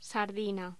Locución: Sardina
Sonidos: Voz humana